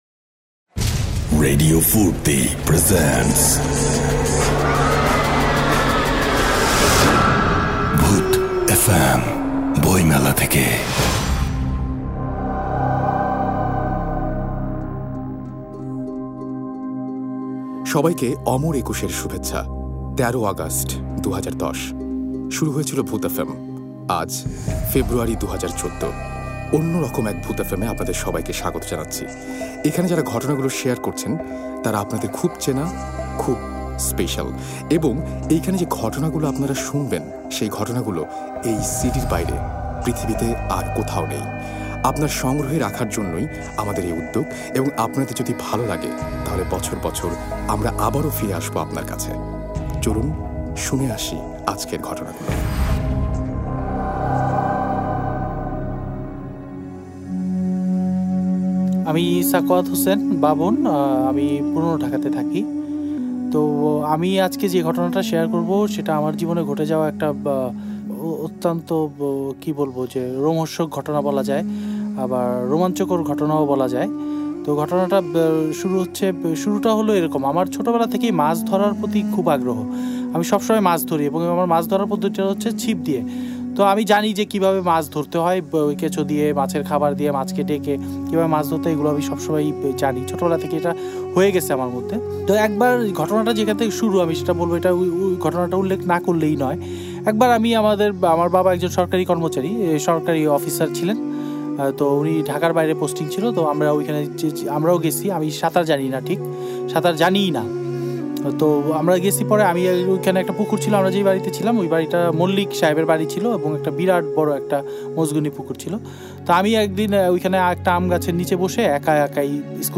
Two guest special episode Bhoot FM 13 Number Oshuvo Songket.